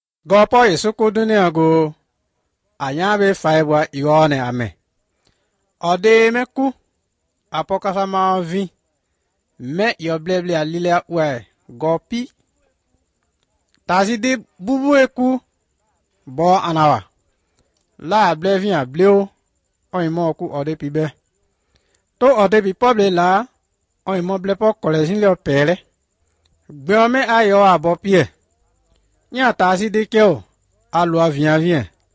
23 April 2017 at 9:20 pm I thought I heard a seven vowel system, which suggests sub-Saharan Africa.
29 April 2017 at 8:33 pm The frequent occurence of nasal vowels makes me think that it might be Boko or an other Mande language.